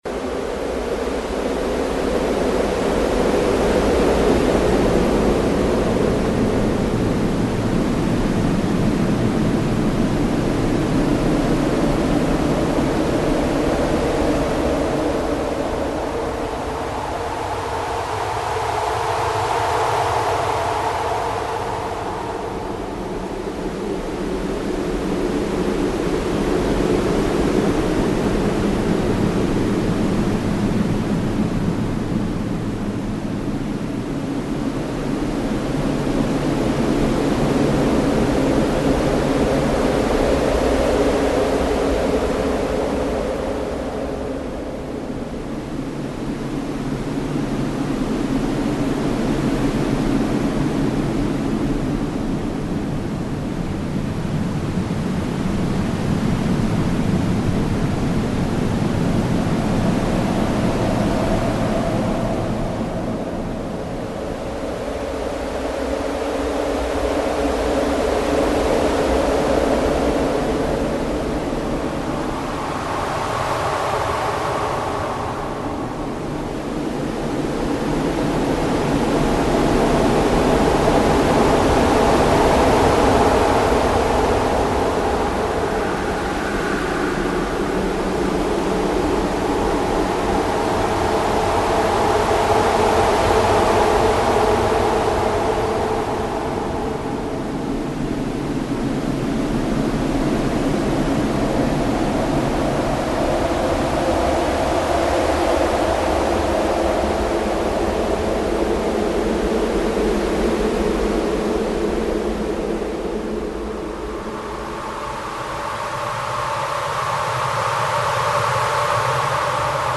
Звуки торнадо
Записи передают всю мощь урагана: свист ветра, скрип разрушающихся конструкций, гул приближающейся бури.